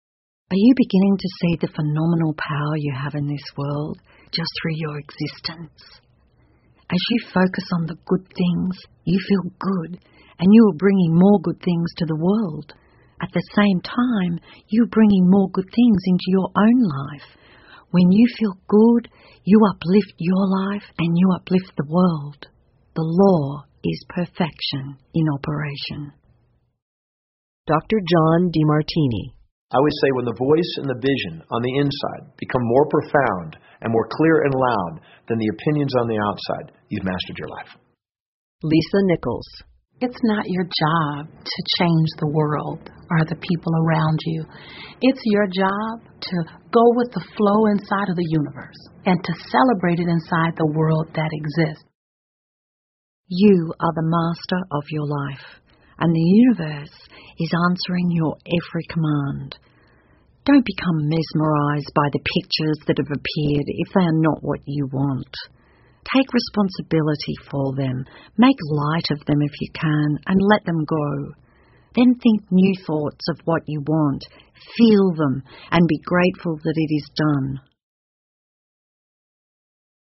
英文有声畅销书-秘密 4-03 The Phenomenal Power You Ha 听力文件下载—在线英语听力室